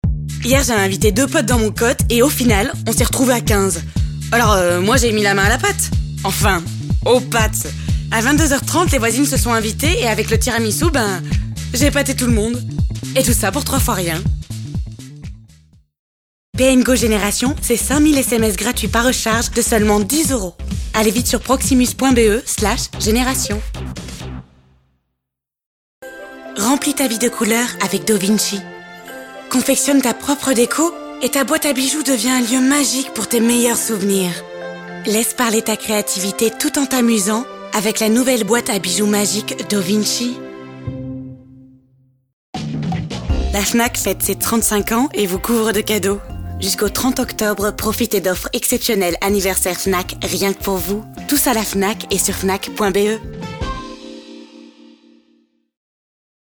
Démo voix pub